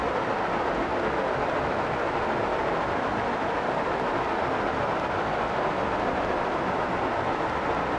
Index of /musicradar/stereo-toolkit-samples/Tempo Loops/120bpm
STK_MovingNoiseC-120_01.wav